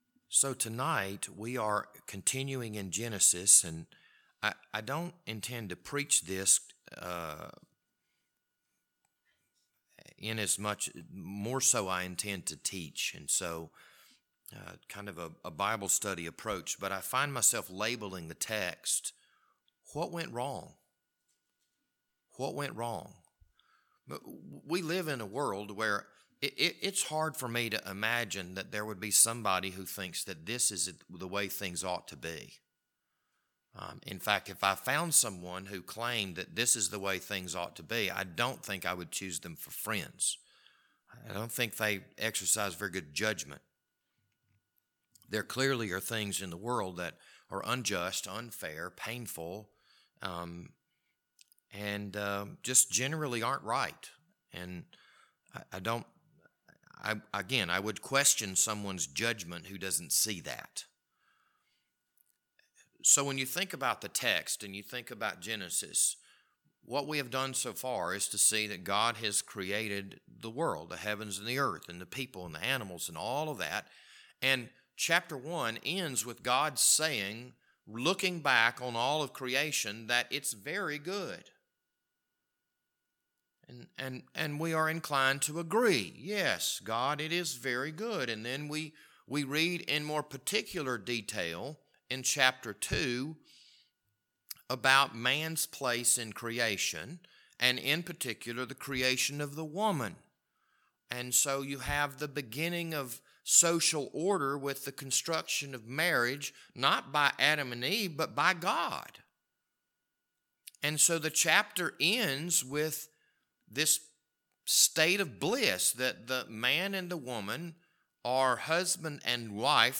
This Wednesday evening Bible study was recorded on April 20th, 2022.